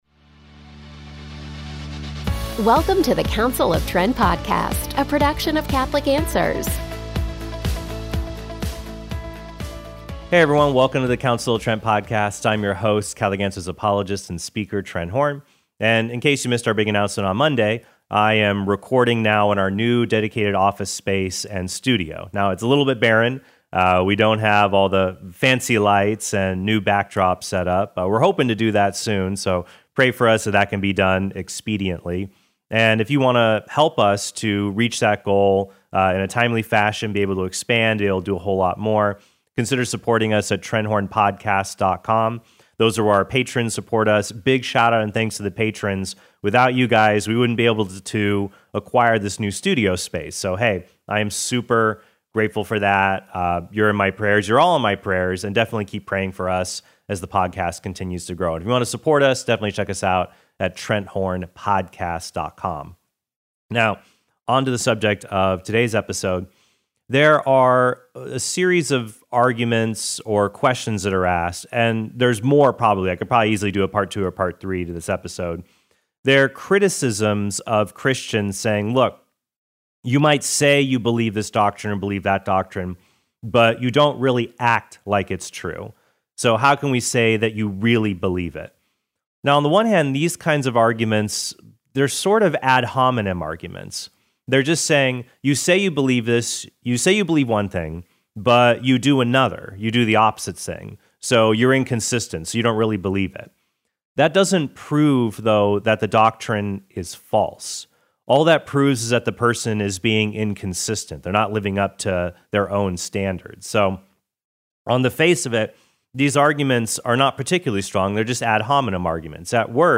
In case you missed our big announcement on Monday, I am recording now in our new dedicated office space and studio.